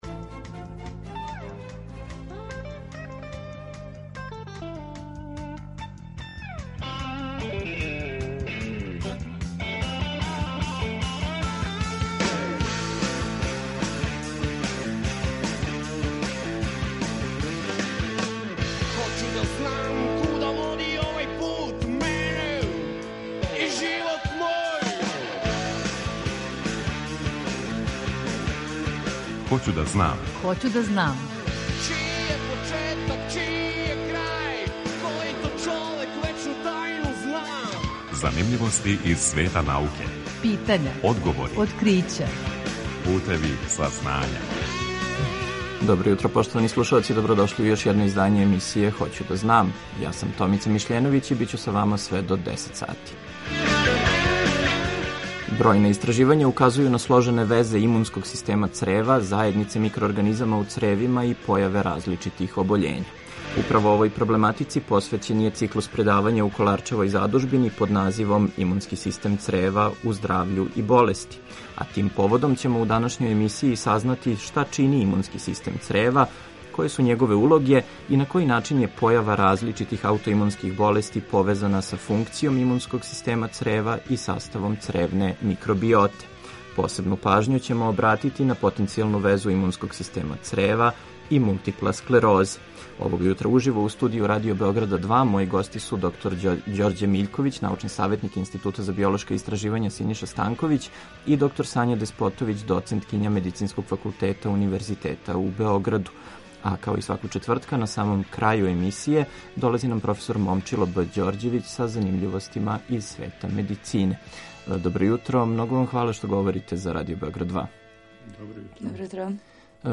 Eмисијa „Хоћу да знам“, посвећенa je популарној науци, која ће сваког четвртка од 9 до 10 сати, почев од 1. октобра 2020. доносити преглед вести и занимљивости из света науке, разговоре са истраживачима и одговоре на питања слушалаца.